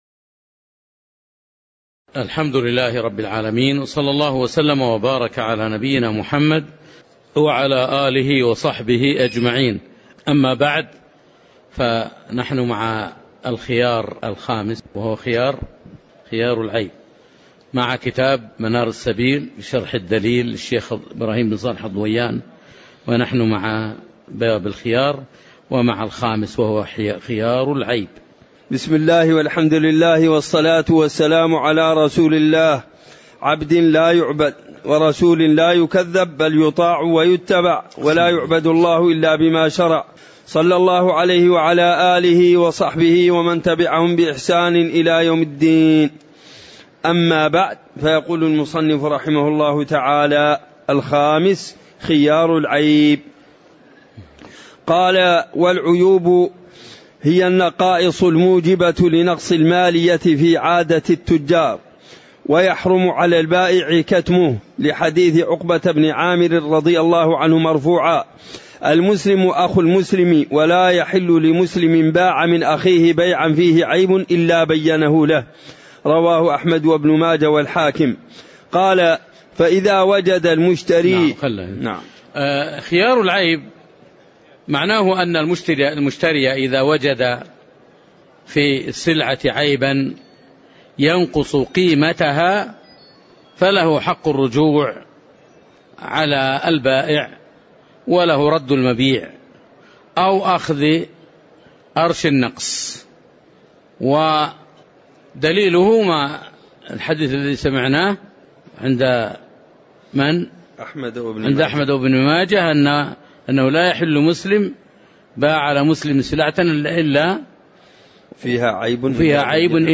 تاريخ النشر ٢٣ محرم ١٤٤٠ هـ المكان: المسجد النبوي الشيخ